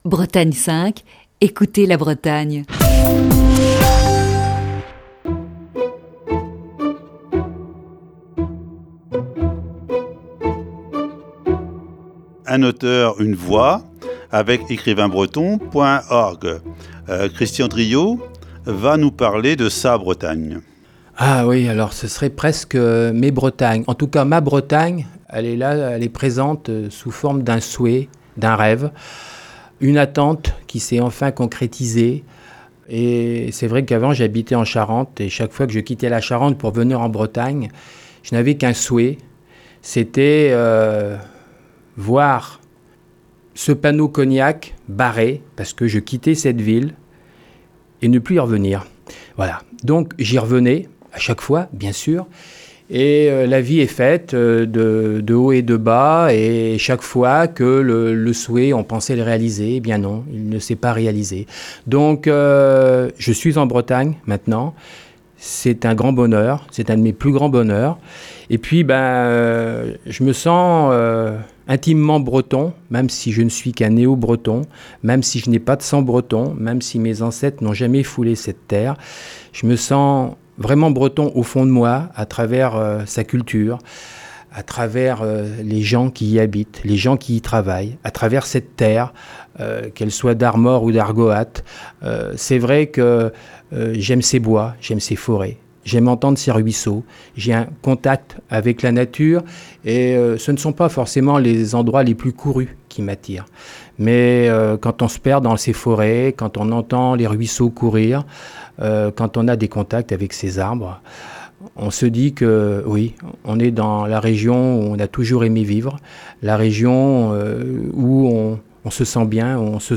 Ce matin, deuxième partie de cet entretien.